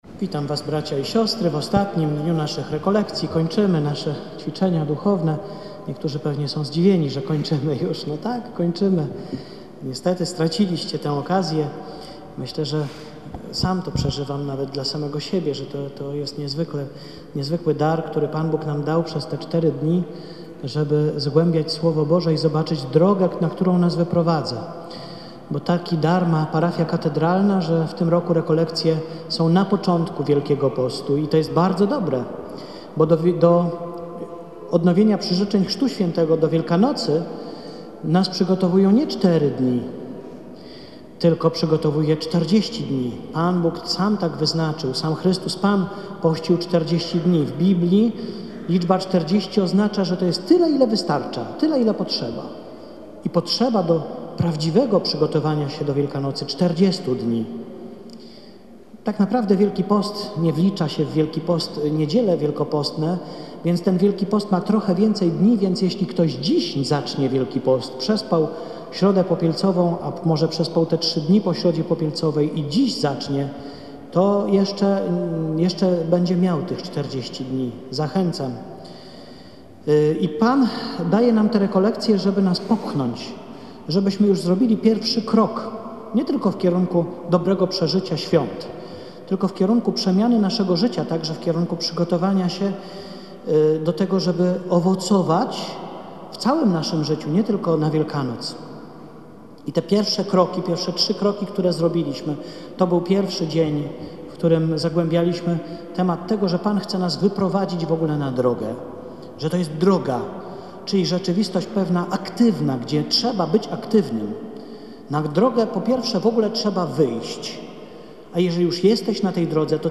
W pierwszą niedzielę Wielkiego Postu, katedra Św. Michała Archanioła i Św. Floriana Męczennika na Pradze jest warszawskim kościołem stacyjnym.
homilia-cala-stacyjna-na-str.mp3